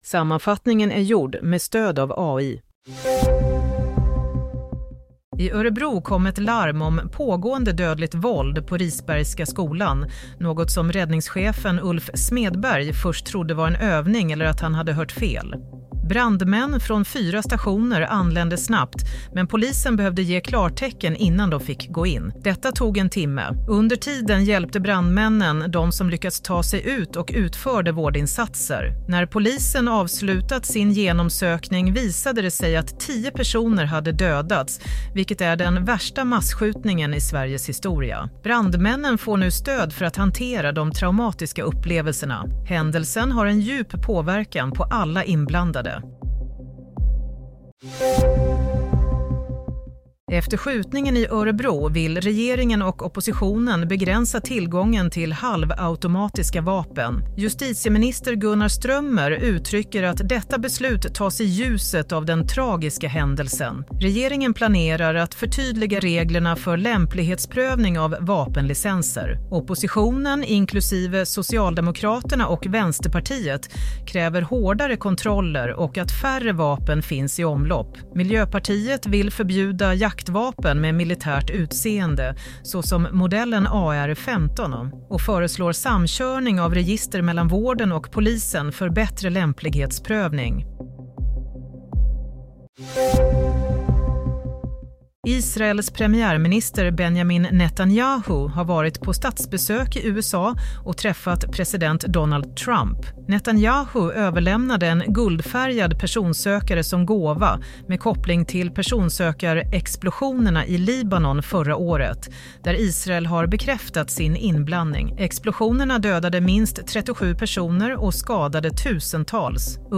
Nyhetssammanfattning - 7 februari 16:00